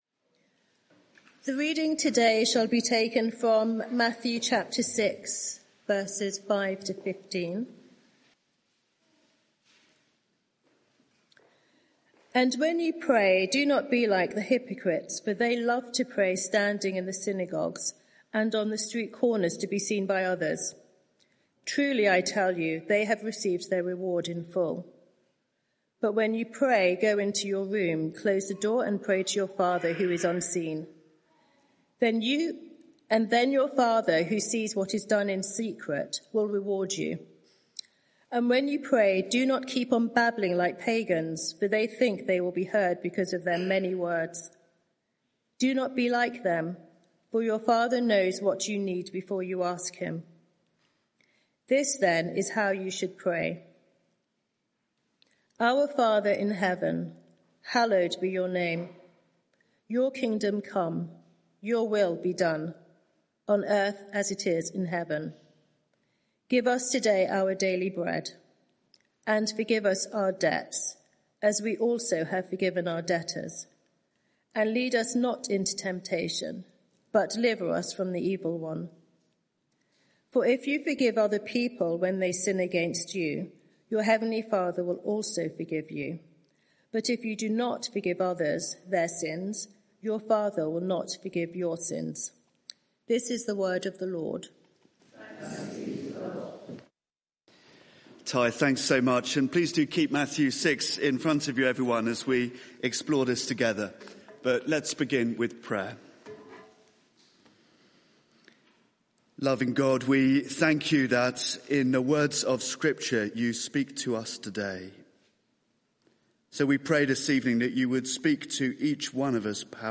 Media for 6:30pm Service on Sun 11th May 2025 18:30 Speaker
Sermon (audio) Search the media library There are recordings here going back several years.